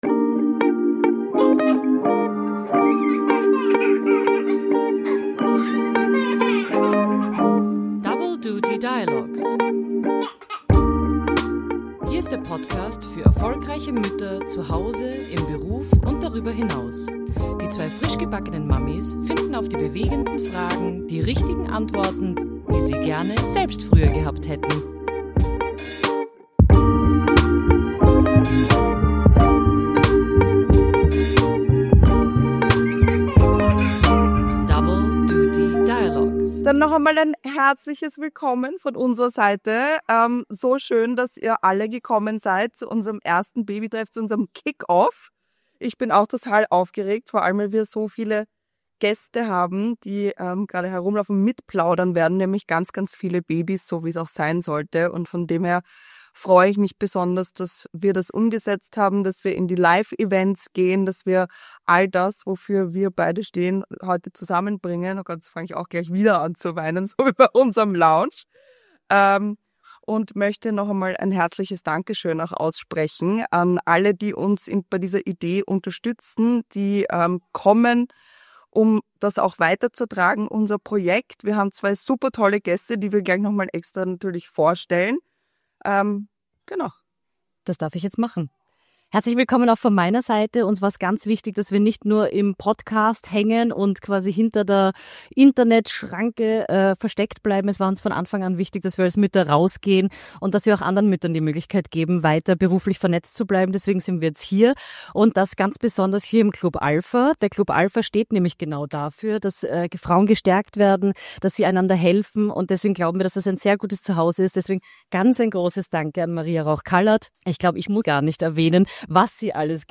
Unsere Babytreffs haben gestartet und wir präsentieren euch unsere Kick-Off Folge unplugged, real und direkt aus dem Herzen.